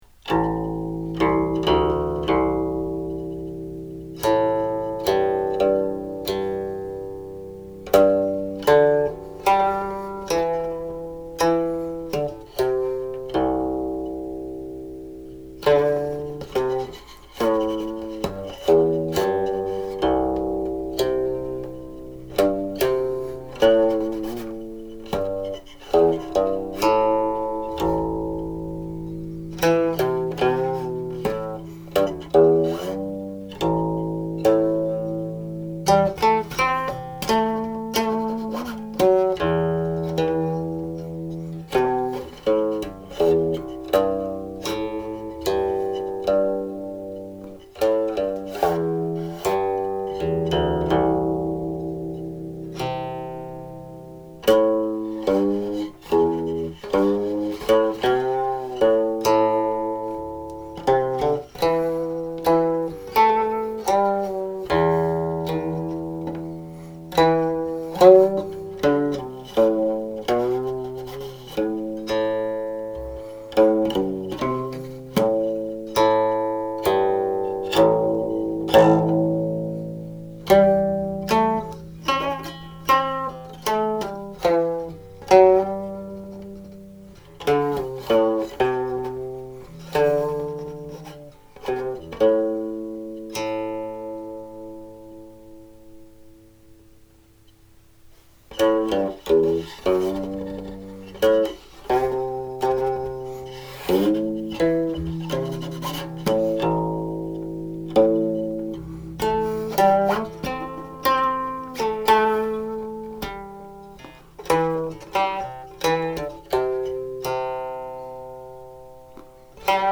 Accordingly, the tentative recording attached here had to be done in segments, as I have not yet memorized the entire piece.
As with many melodies using this tuning, the tonal centers shift between la with mi and do with so.